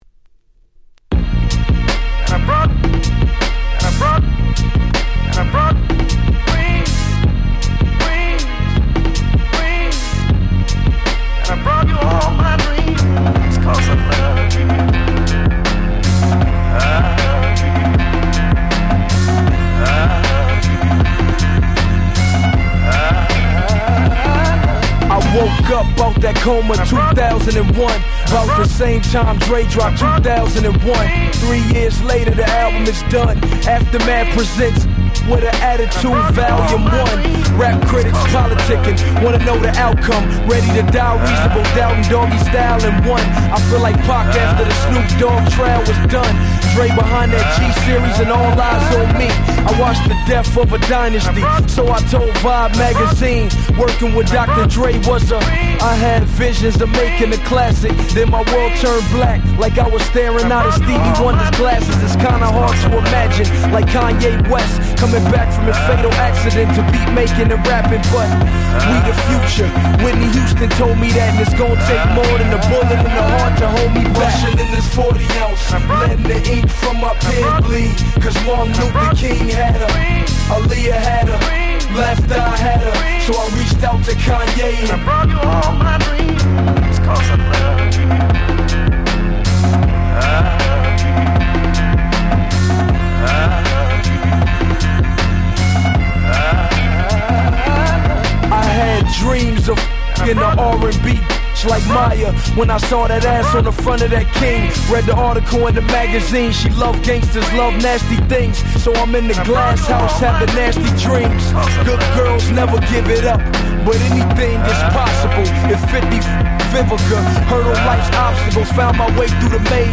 DJ用にイントロにBreakがついて繋ぎ易くなってたりするアレです。